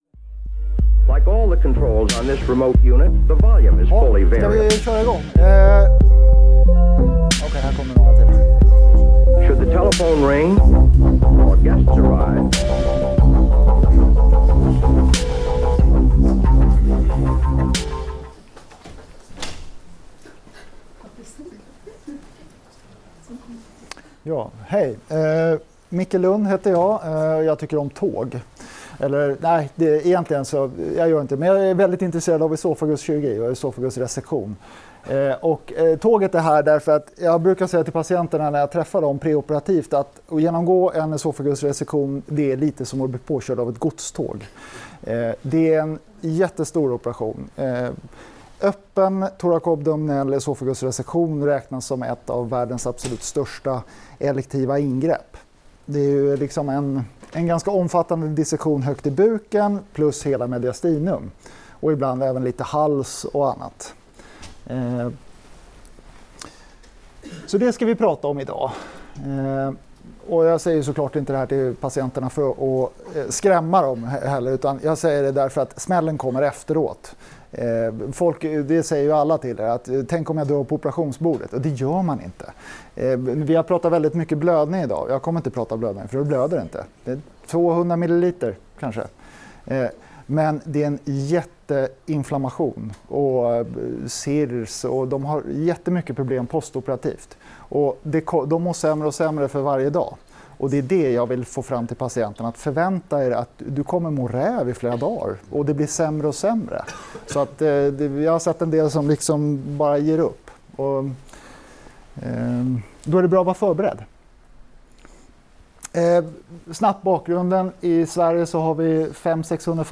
Inspelningen gjordes under ST-fredagen om övre abdominell kirurgi på Huddinge 2018-11-16.